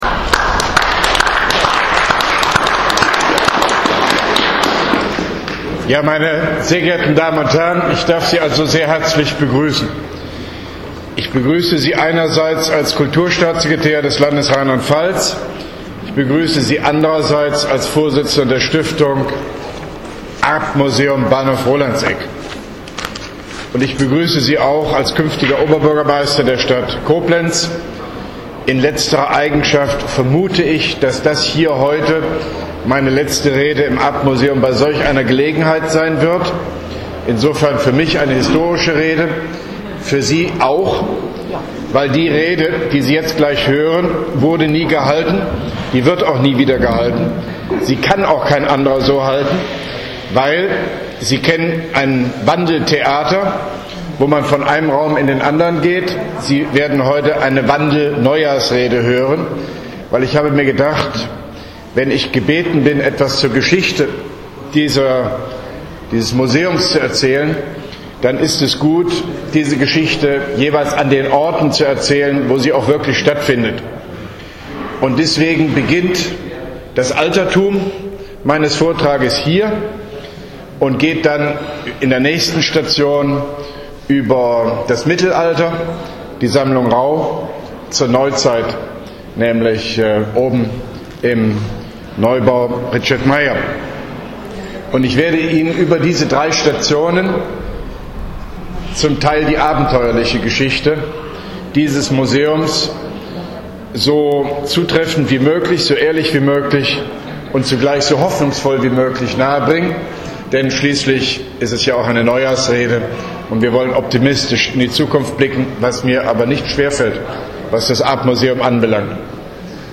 Arp Vortrag Erste Station
Arp-Vortrag-Erste-Station.mp3